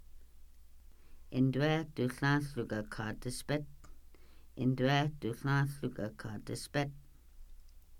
Individual audio recordings of Kaska words and phrases about eating and drinking preferences. This subset of the original Hunger, Eating, and Drinking Deck contains exchanges about hunger.